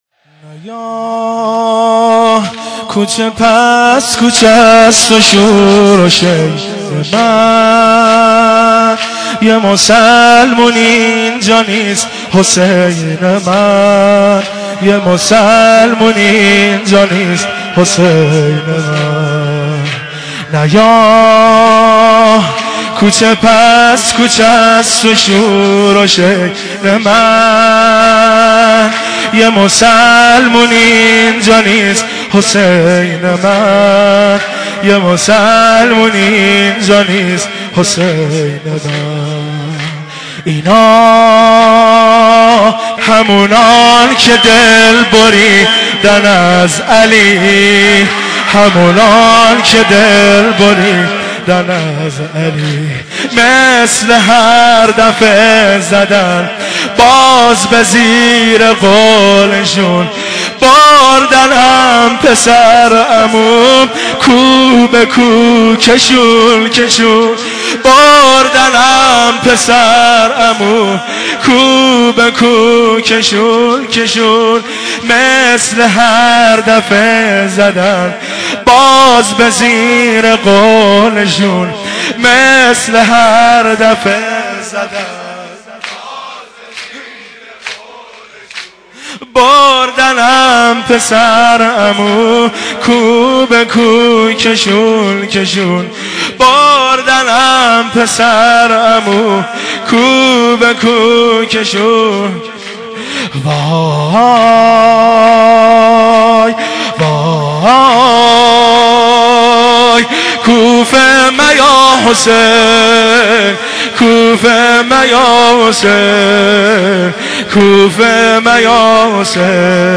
مداحی نوحه امام حسین (ع)